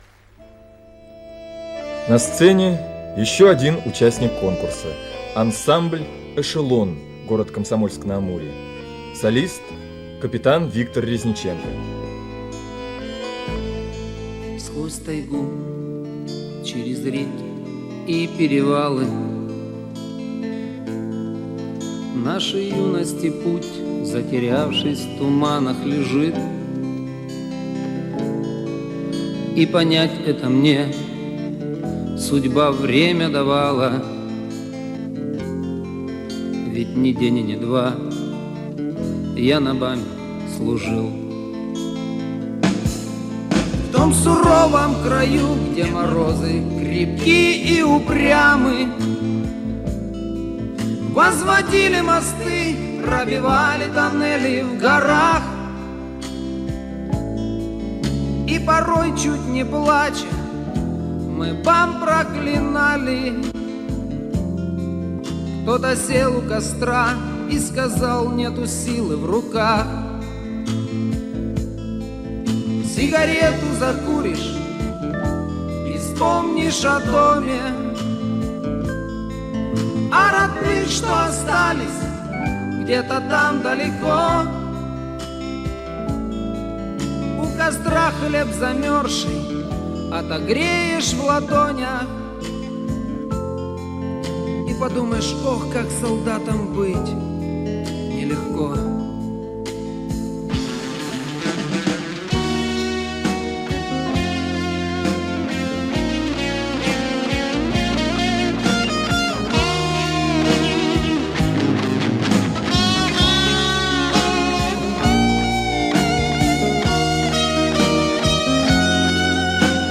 Музыкальное сопровождение: песня "Я на БАМе служил"